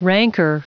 Prononciation du mot rancor en anglais (fichier audio)
Prononciation du mot : rancor